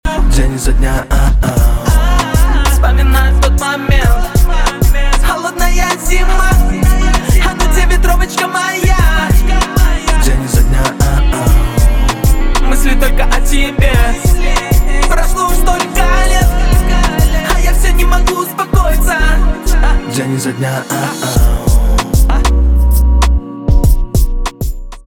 русский рэп
грустные